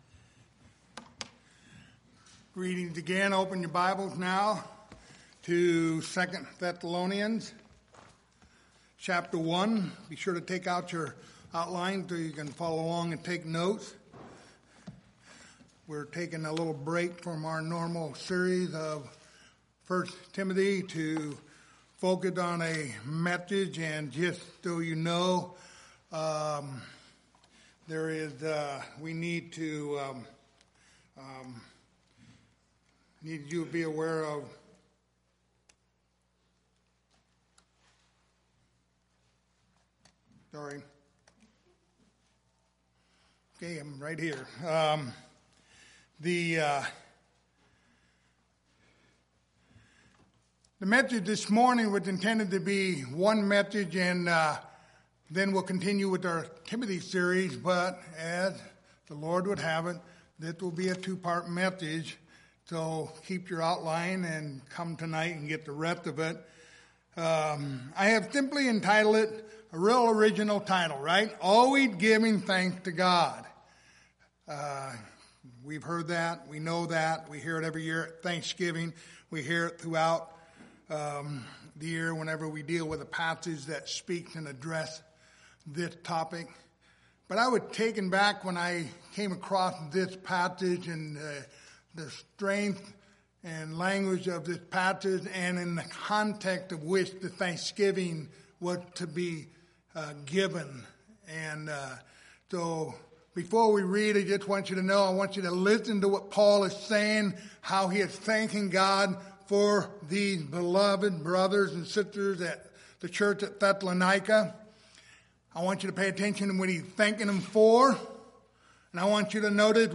Passage: 2 Thessalonians 1:3-4 Service Type: Sunday Morning